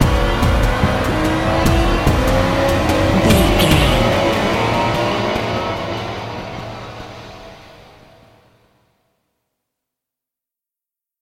Thriller
Aeolian/Minor
synthesiser
drum machine